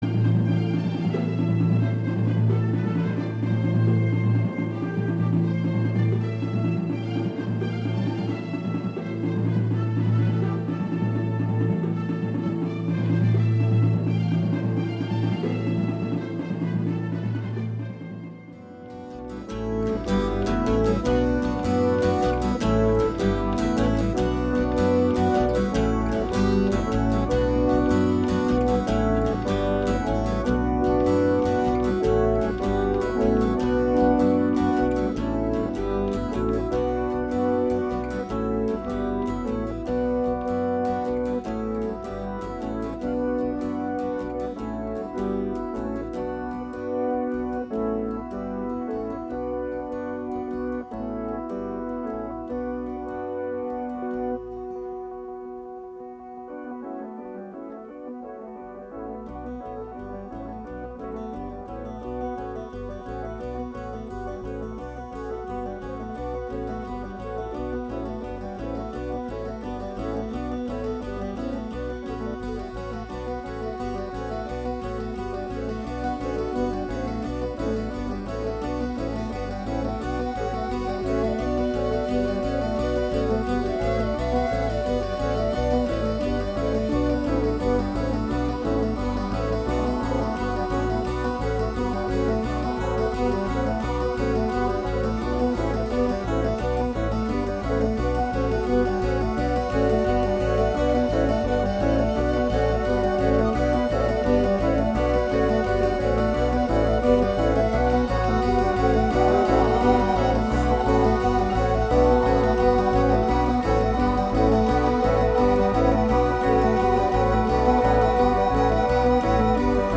Since 2014, Hoot 'N Howl is XRAY FM's original oldies radio show! Tune in every Sunday for real, rare, and rockin' vinyl of the 1940's thru late 1960's in a cross genre blend of scratchy classics and hidden gems!